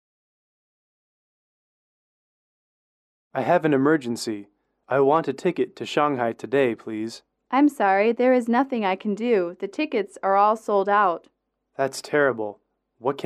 英语口语情景短对话21-1：买票去上海(MP3)